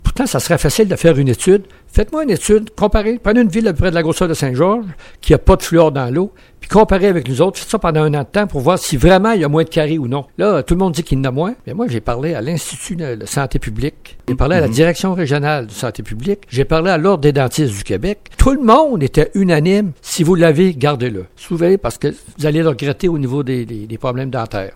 Lors d’une entrevue à Radio-Beauce, le maire Morin explique que le fluor peut même avoir certains aspects positifs.